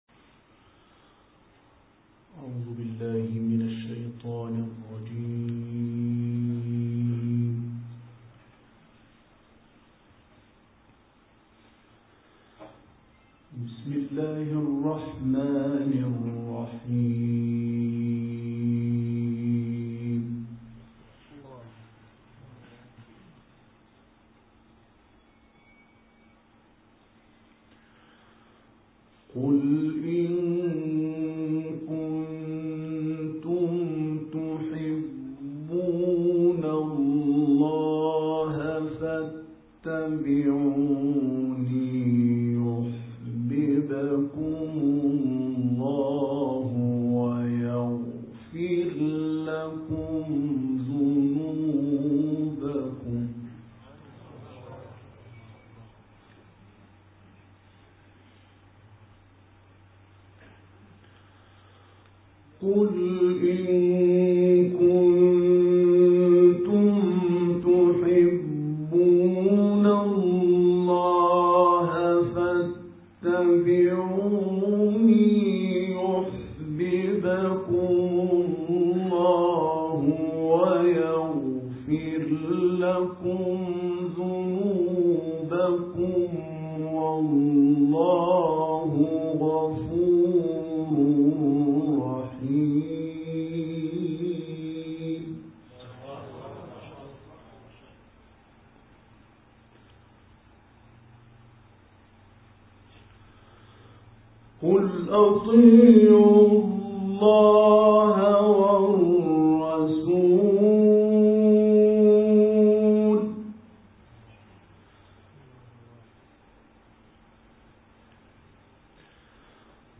تلاوت سوره آل‌عمران «هنداوی» در انگلیس
گروه شبکه اجتماعی: تلاوت آیاتی از سوره مبارکه آل ‌عمران با صوت حجاج هنداوی را که در انگلیس اجرا شده است، می‌شنوید.
این تلاوت در انگلیس اجرا شده و مدت زمان آن 31 دقیقه است.